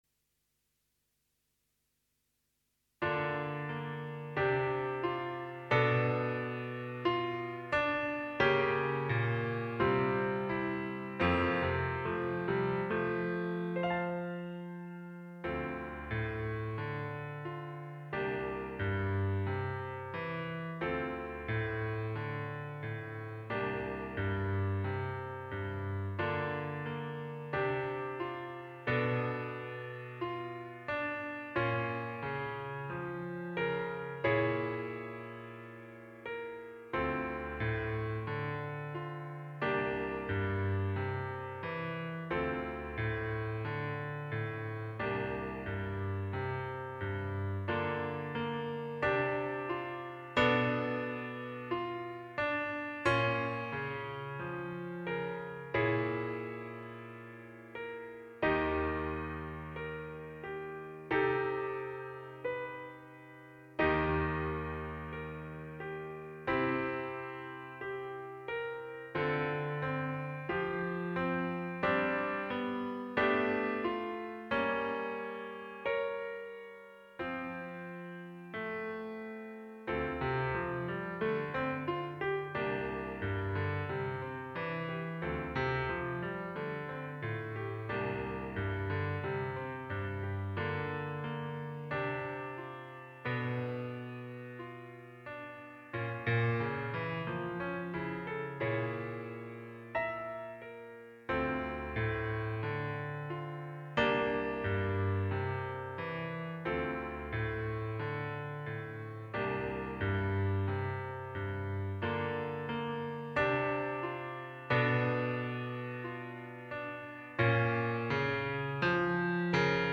minus solo